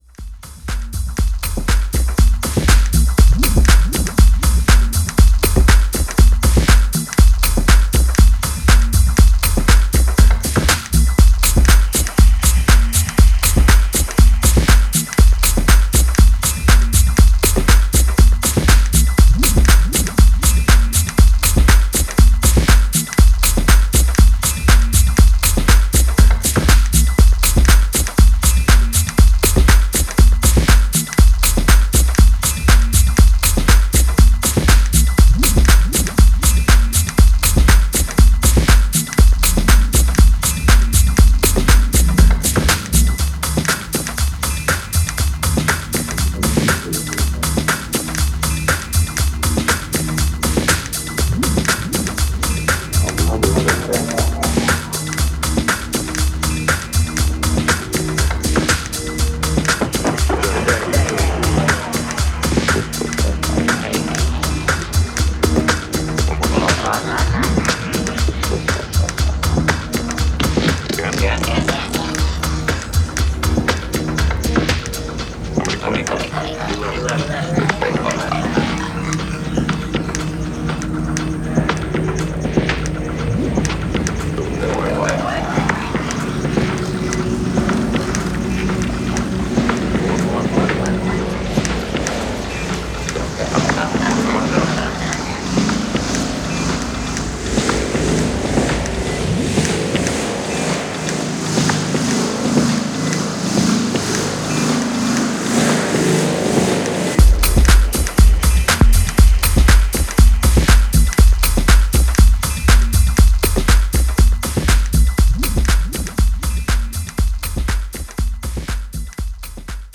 you can imagine dancefloors going wild to its hooky rhythms.
Style: Techno / Deep - Experimental - Minimal